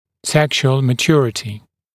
[‘sekʃuəl mə’ʧuərətɪ][‘сэкшуэл мэ’чуэрэти]половая зрелость